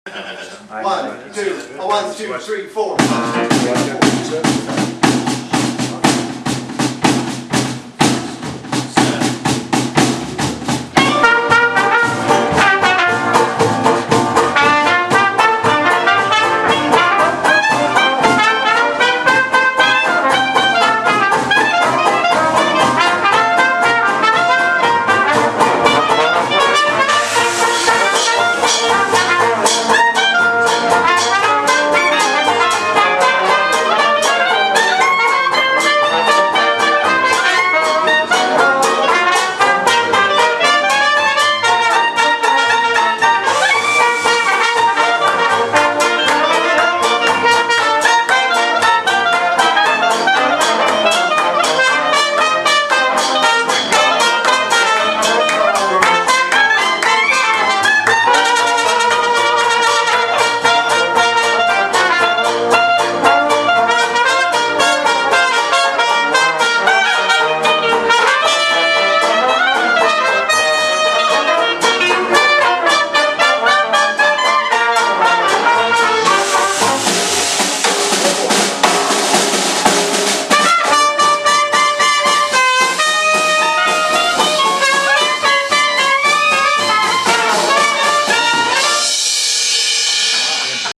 ADULT TRADITIONAL JAZZ CAMP 2010
San Diego Traditional Jazz Camp 2010, San Diego, CA: Jan14-17, 2010, Lafayette Hotel
Bass
Banjo
Clarinet
Trombone
Trumpet
Piano